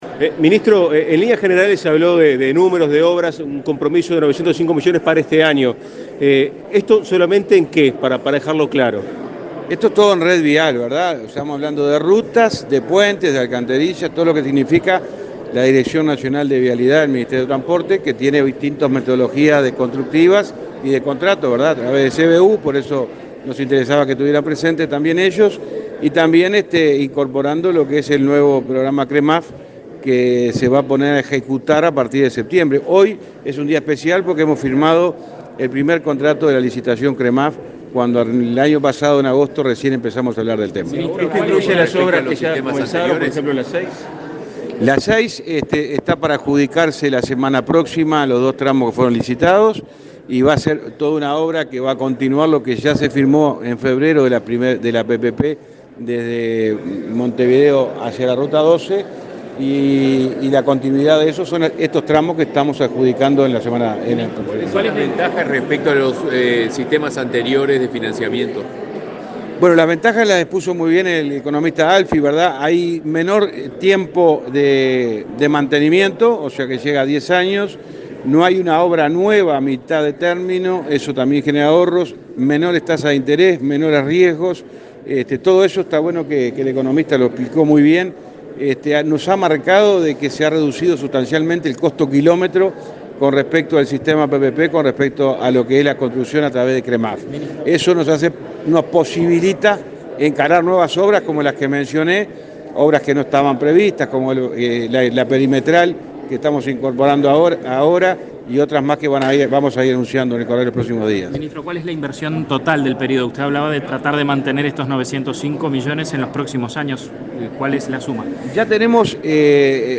Declaraciones a la prensa del ministro de Transporte y Obras Públicas, José Luis Falero
Declaraciones a la prensa del ministro de Transporte y Obras Públicas, José Luis Falero 05/07/2022 Compartir Facebook Twitter Copiar enlace WhatsApp LinkedIn Este martes 5, el Gobierno presentó el Plan Nacional de Infraestructura Vial 2020-2025. Tras el evento, el ministro Falero efectuó declaraciones a la prensa.